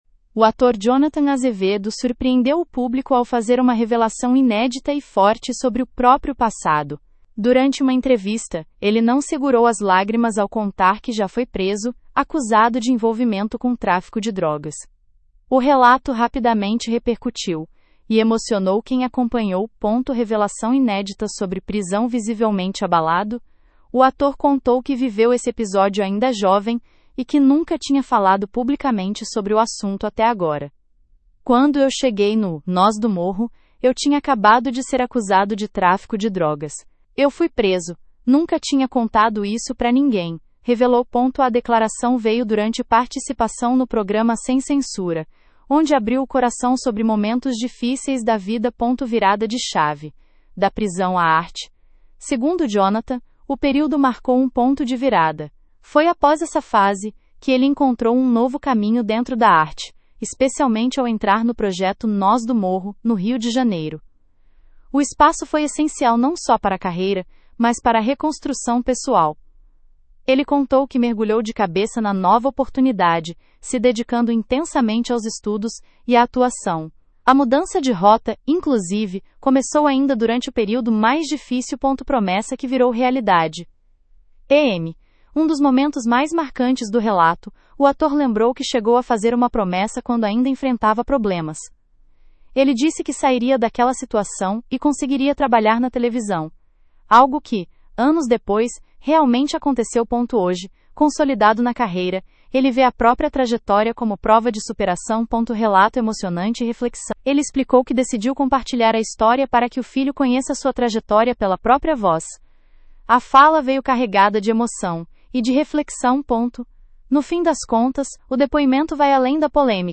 Jonathan Azevedo chora ao revelar prisão por tráfico e faz desabafo emocionante sobre o passado
Visivelmente abalado, o ator contou que viveu esse episódio ainda jovem e que nunca tinha falado publicamente sobre o assunto até agora.
A declaração veio durante participação no programa Sem Censura, onde abriu o coração sobre momentos difíceis da vida.
A fala veio carregada de emoção — e de reflexão.